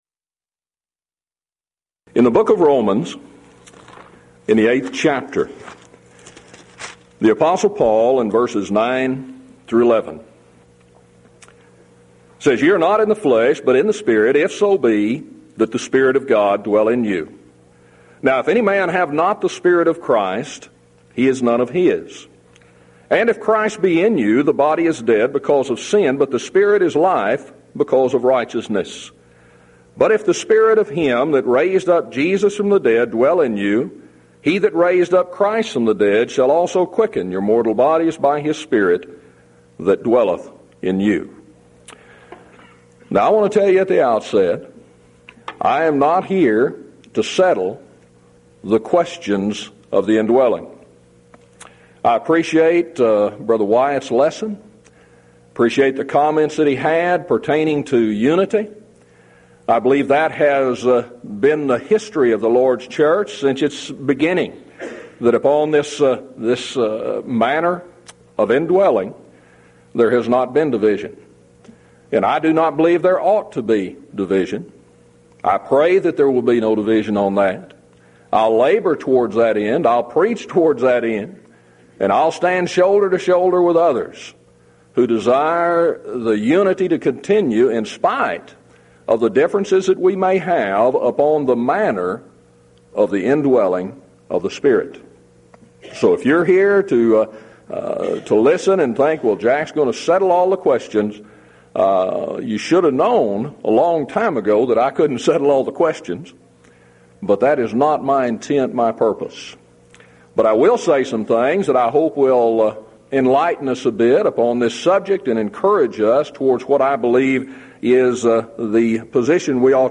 Event: 1997 Mid-West Lectures